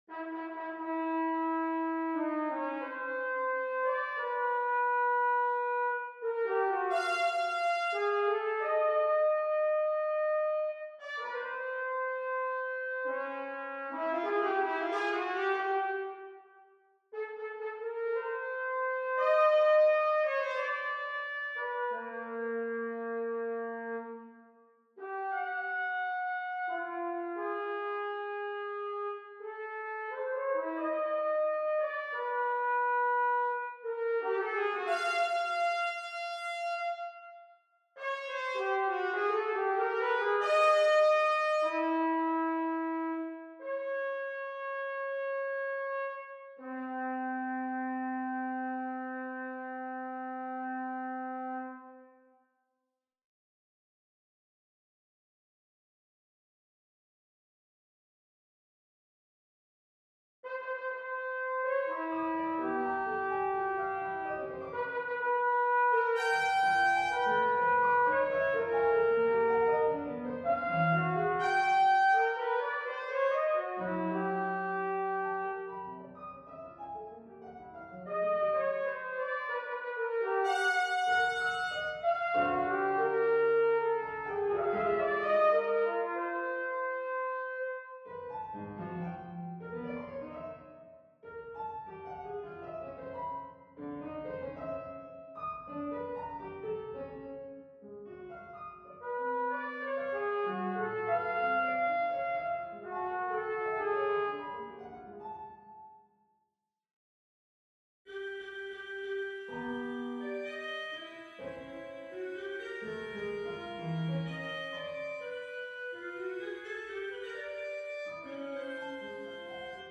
Audio demo of 2015 version:
quiet, mysterious piece